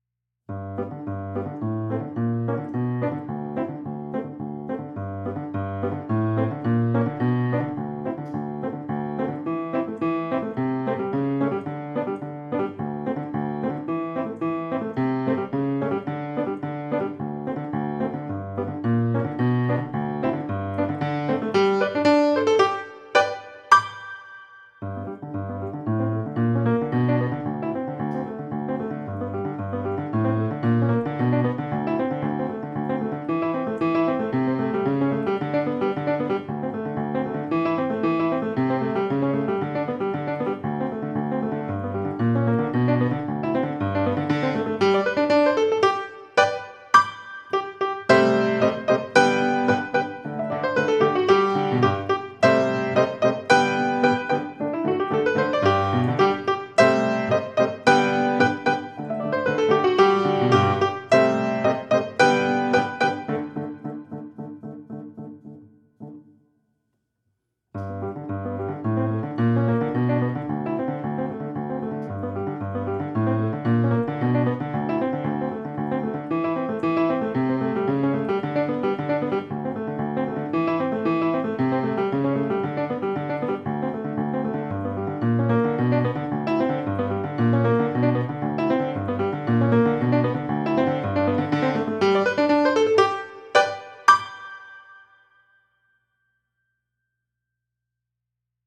Piano, Música pedagogica